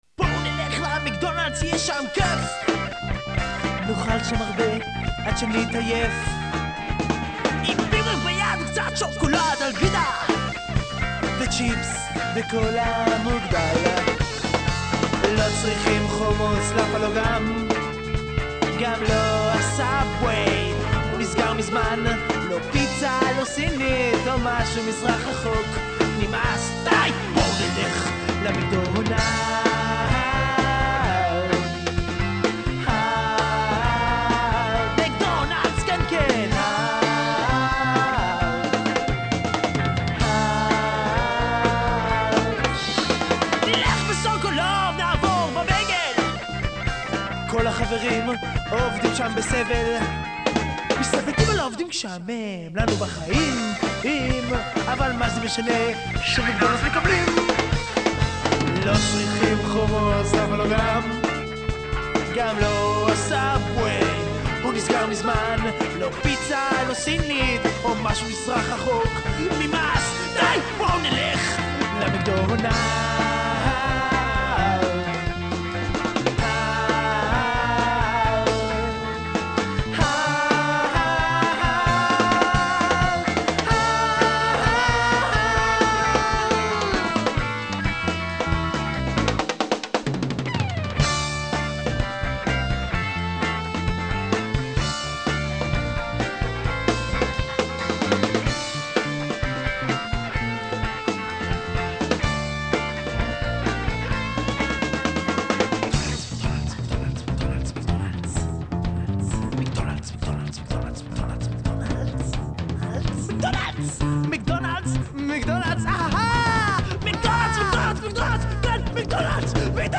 Studio Demo